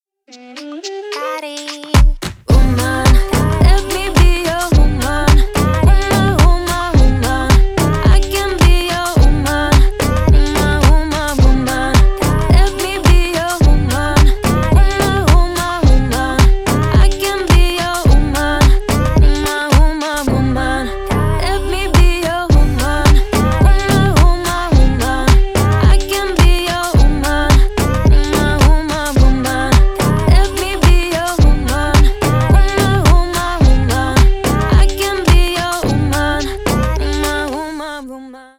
Танцевальные
клубные # кавер